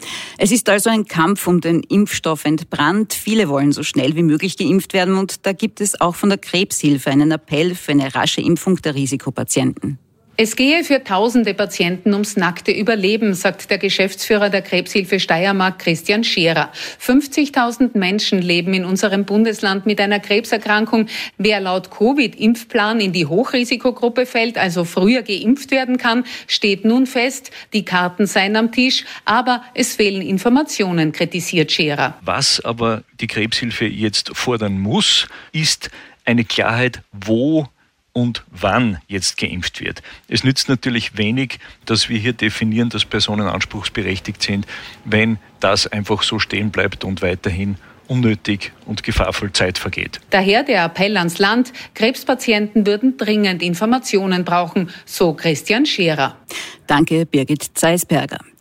ORF-Interview